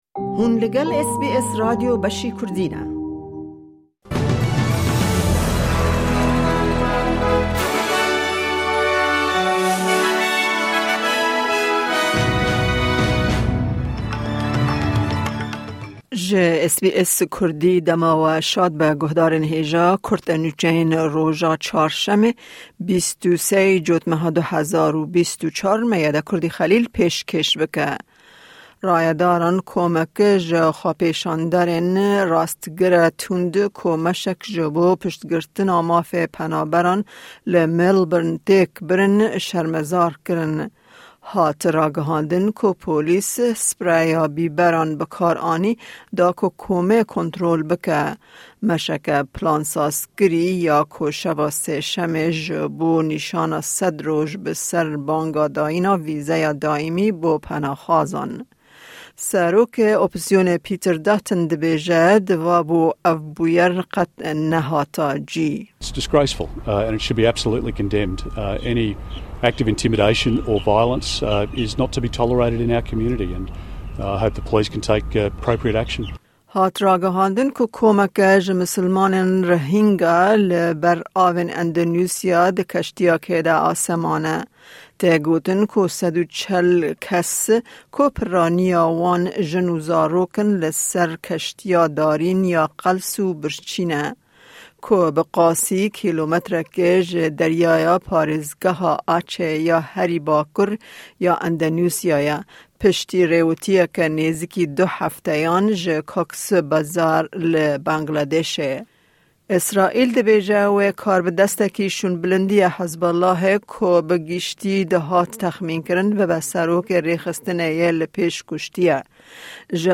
Kurte Nûçeyên roja Çarşemê 23î Îlona 2024